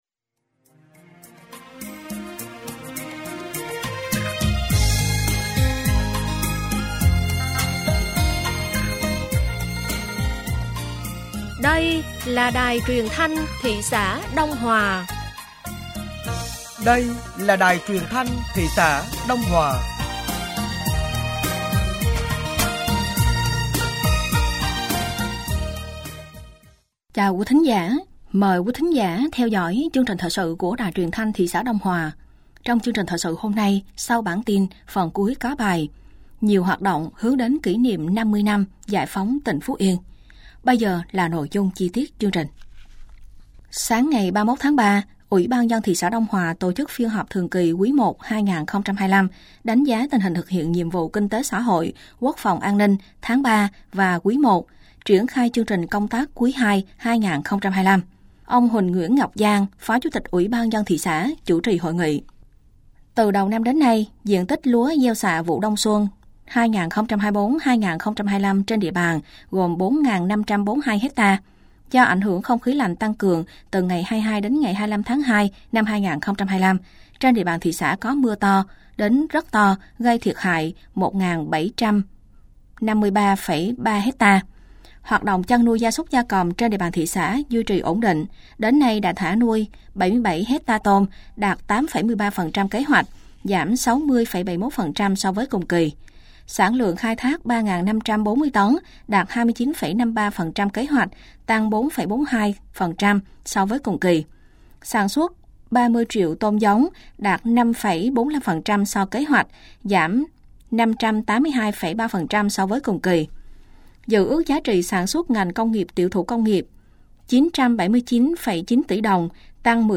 Thời sự tối ngày 31 th3 và sáng ngày 01 tháng 4 năm 2025